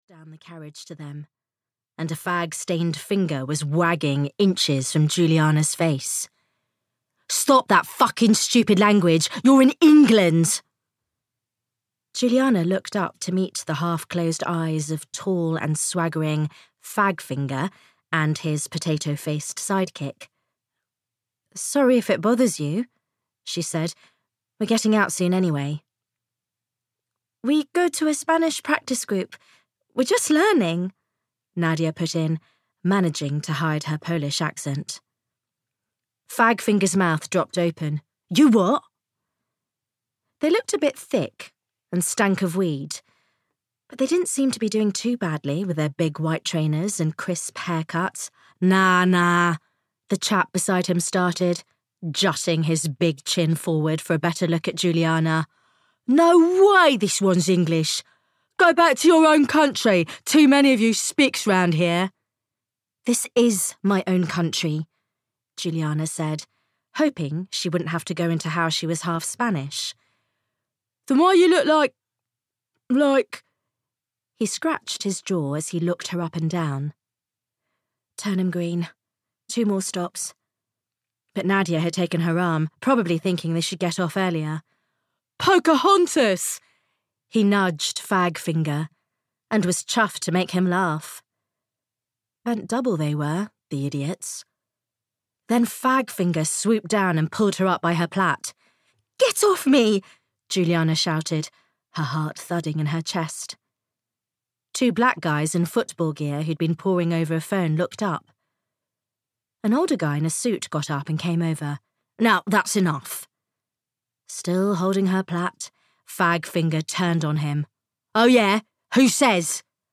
Audiobook The Spanish house written by Cherry Radford.
Ukázka z knihy